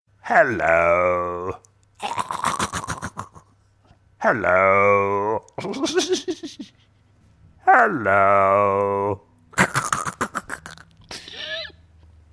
• Качество: 128, Stereo
смех